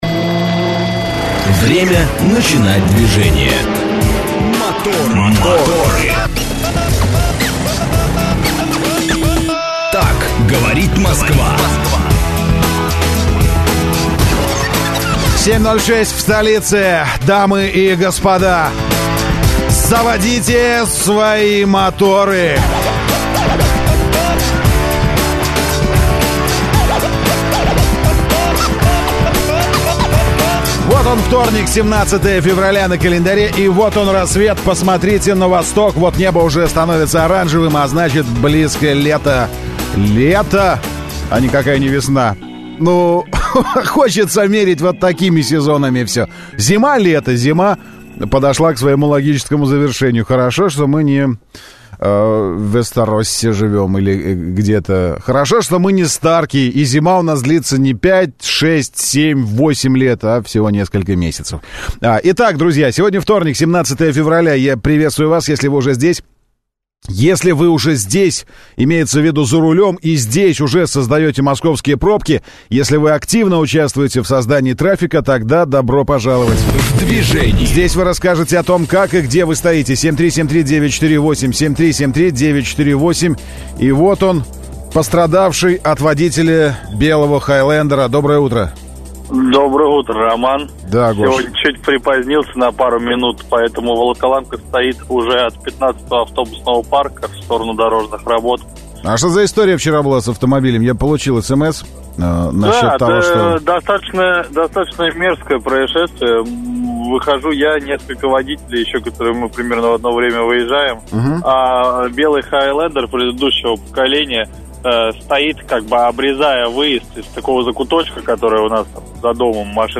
Утренняя программа для водителей и не только. Ведущие рассказывают о последних новостях автомобильного мира, проводят со слушателями интерактивные «краш-тесты» между популярными моделями одного класса, делятся впечатлениями от очередного тест-драйва.